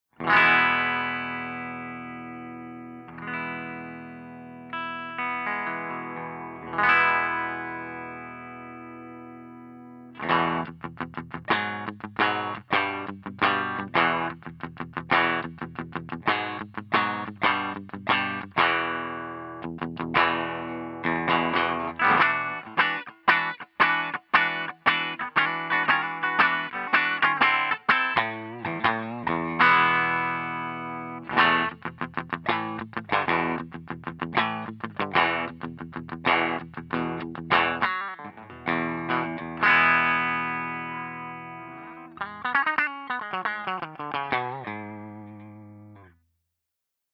044_FENDER75_BRIGHT_P90